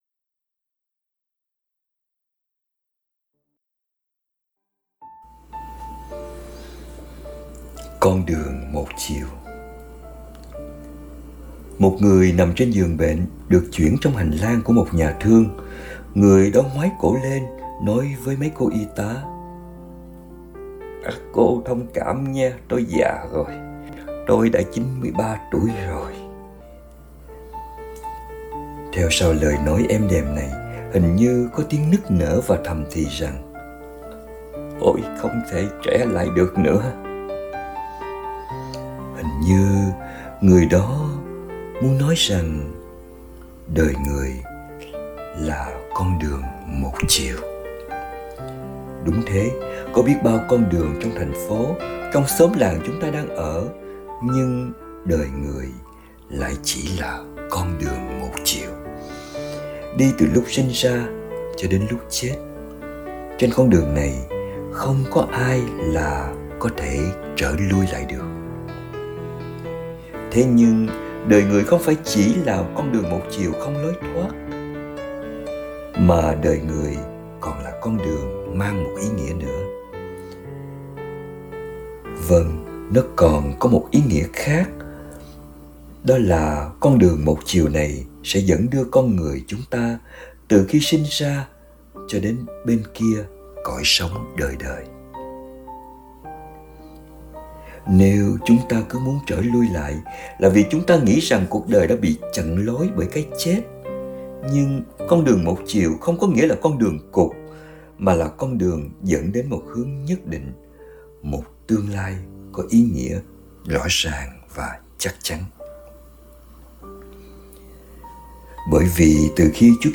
2024 Audio Suy Niệm https